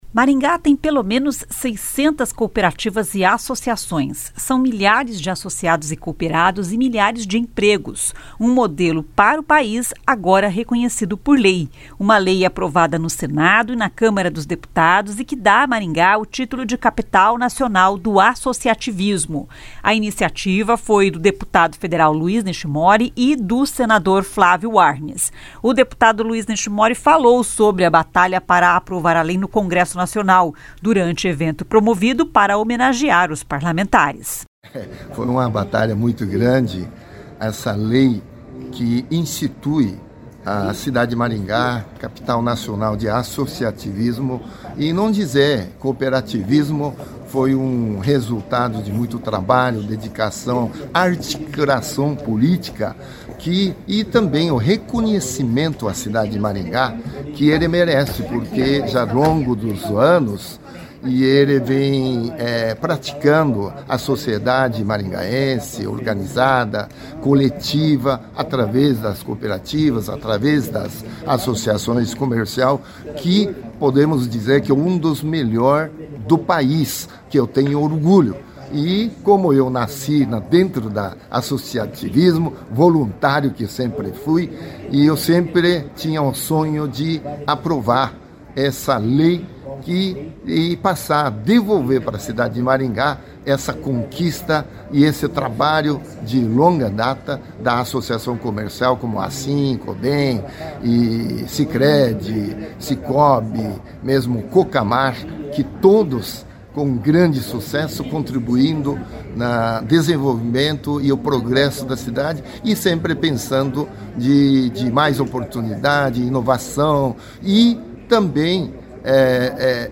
O deputado Luiz Nishimori falou sobre a batalha para aprovar a lei no Congresso Nacional, durante evento promovido para homenagear os parlamentares.